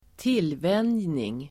Ladda ner uttalet
tillvänjning substantiv, training , habituation Uttal: [²t'il:vän:jning] Böjningar: tillvänjningen Definition: det att bli van (och beroende) habituation substantiv, tillvänjning Förklaring: det att bli van (och beroende)